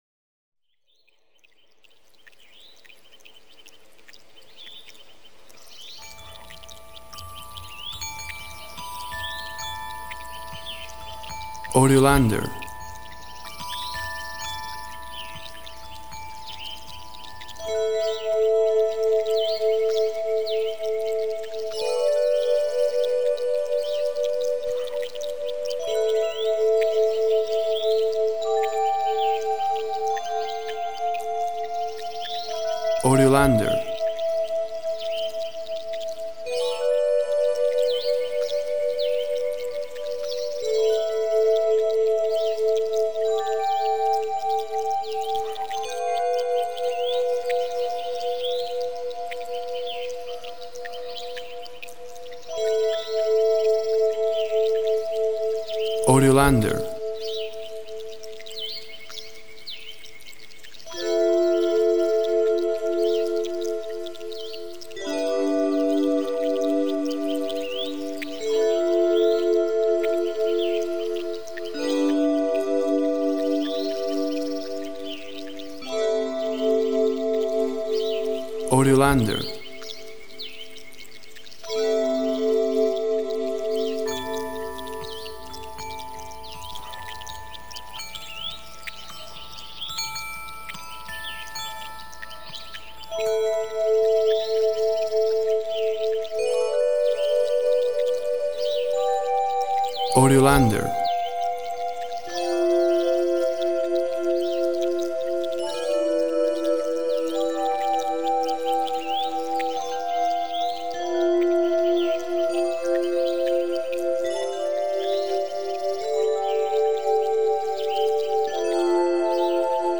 Wind, birds, chimes create an outdoor tapestry of sound.
Tempo (BPM) 56